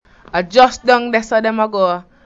//aː/weɪ dem dɪd deɪ//
Notice the /d/ pronunciation of all instances where RP would have /ð/.